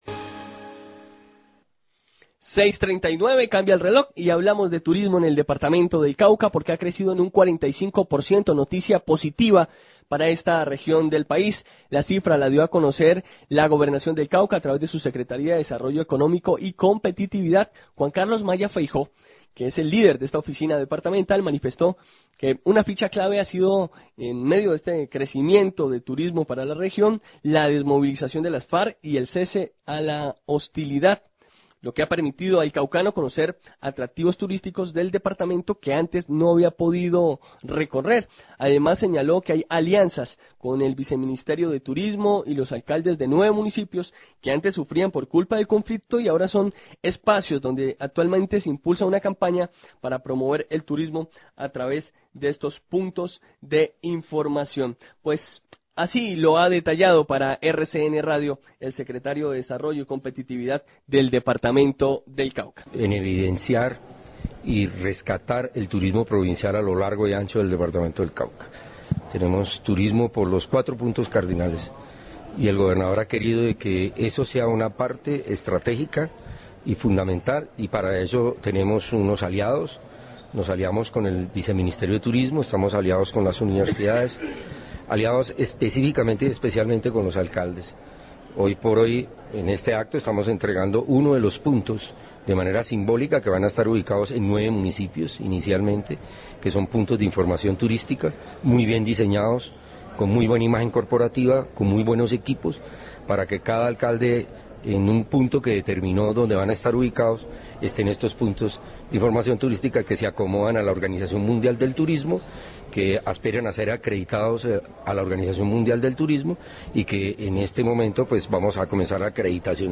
Radio
Se entregó de manera simbólica el punto de información turístico de Suárez que brinda herramientas para impulsar el turismo de Suárez, su gastronomía, su paisajismo con La Salvajina, su gastronomía. Declaraciones del Secretario de Desarrollo Económico y Competitividad del Cauca, Juan Carlos Freijo y del Alcalde de Suárez, Hernando Ramirez.